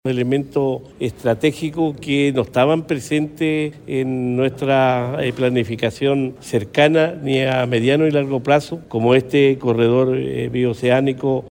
Fue el Salón Mural del Gobierno Regional el que albergó la reunión.